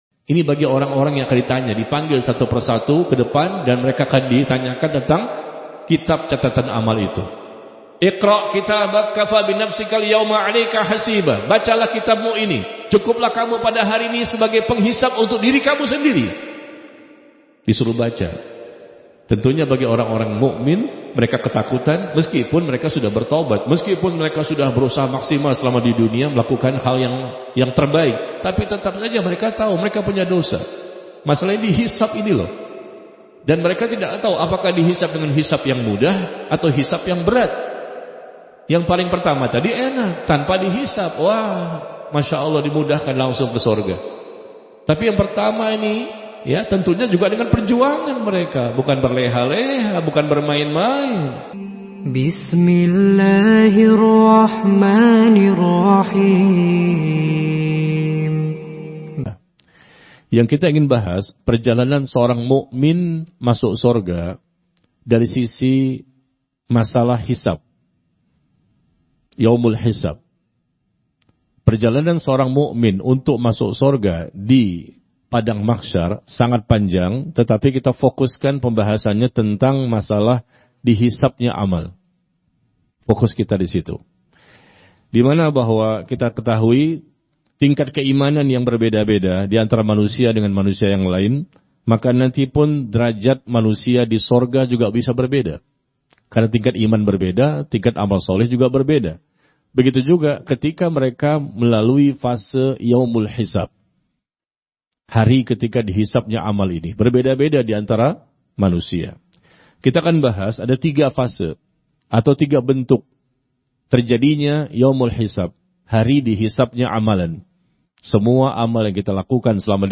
AUDIO KAJIAN